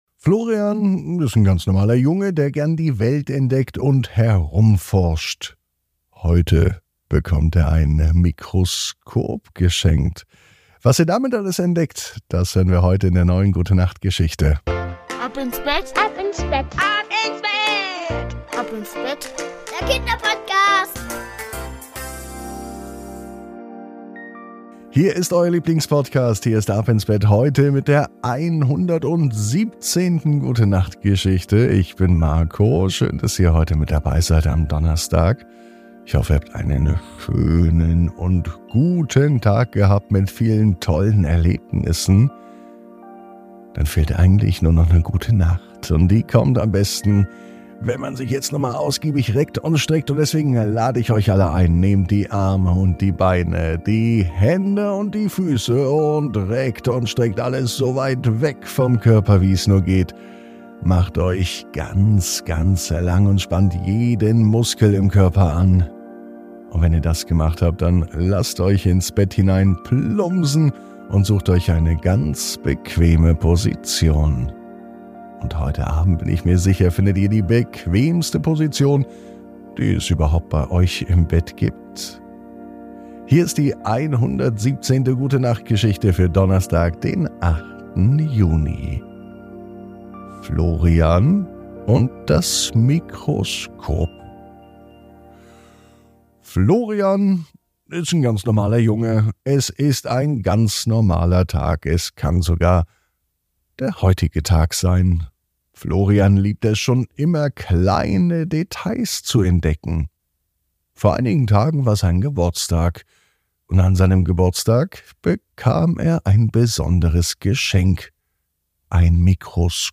Die Gute Nacht Geschichte für Donnerstag